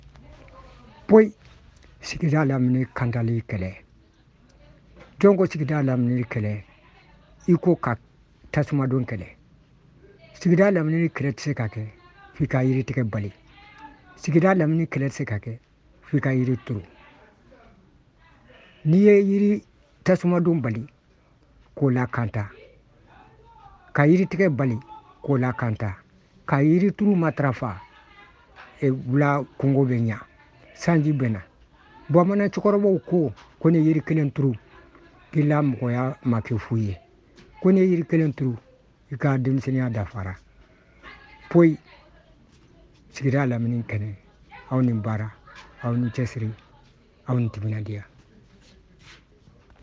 Reportage-Monzona-5.ogg